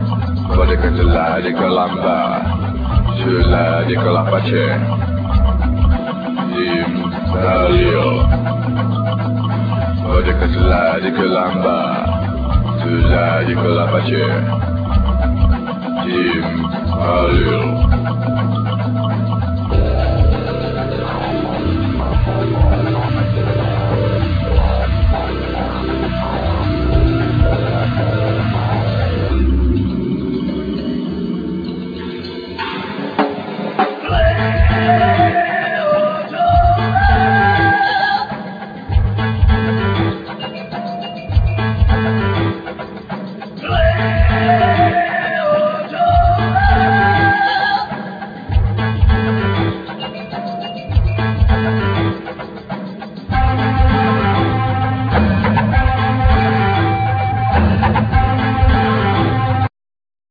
Vocal,Throat vocal,Djembe,Rattles,Indian flute,Sounds
Drums,Percussions,Groove box,Synth,Loop
Bass Hammond,Synth,Sample
Guitar loop